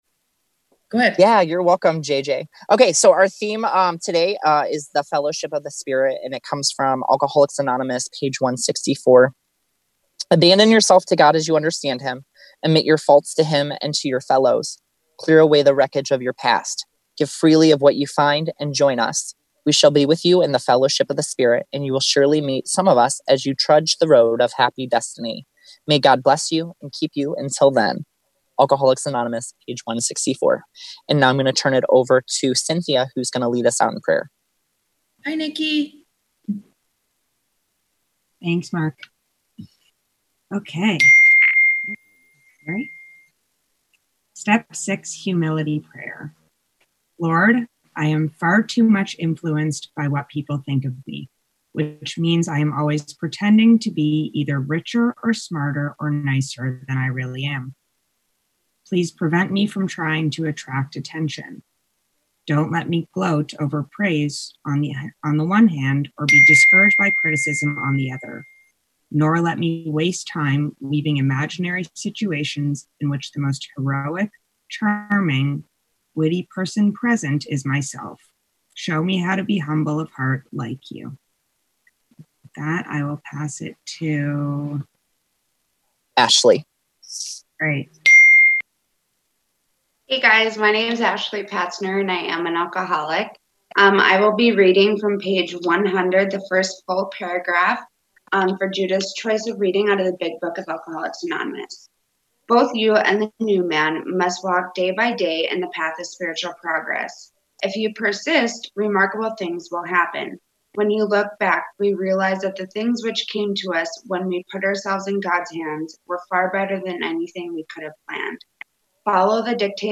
Woodstock Conference Young People In AA Sterling Heights MI